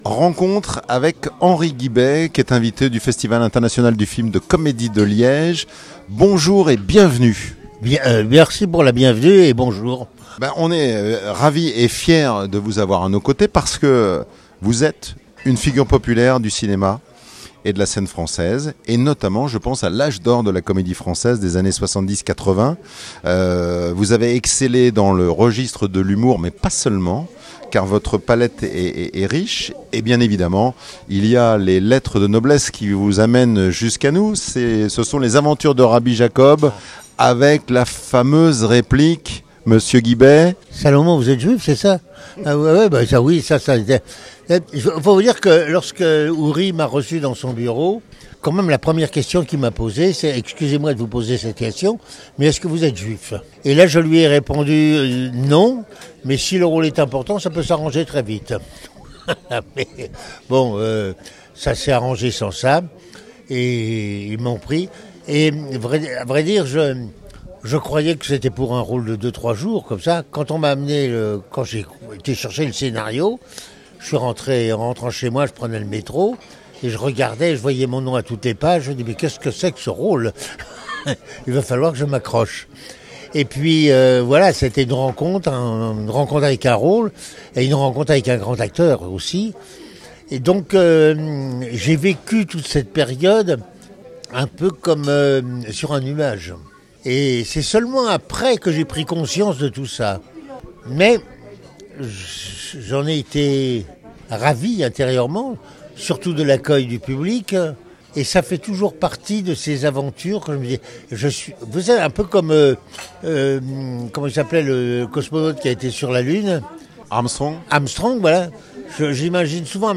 Les podcasts, interviews, critiques, chroniques de la RADIO DU CINEMA